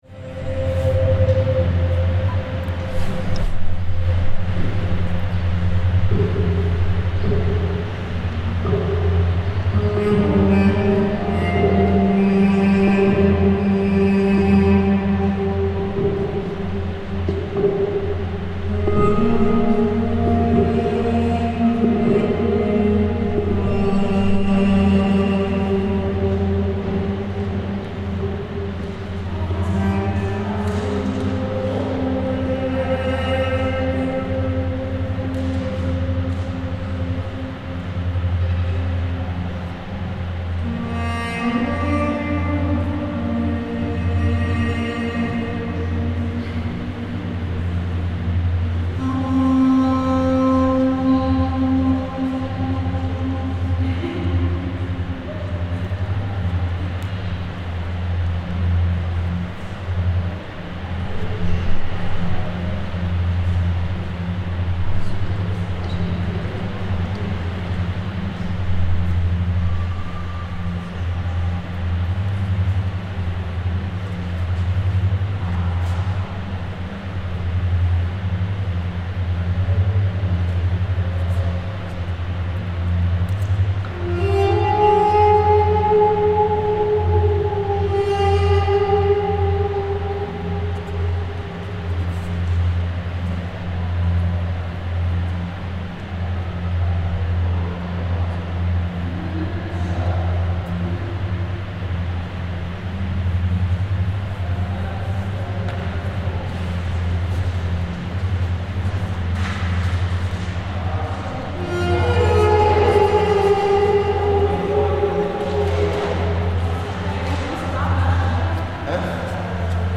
Inside the Klimahaus museum, which is dedicated to telling the stories of climate and the environment.
The exhibition called "The Journey" takes you around the world on a line of longitude from Bremerhaven with interactive installations and exhibits that make extensive use of sound to be as immersive as possible. Here we are in Niger, experiencing a video installation based on desert village life.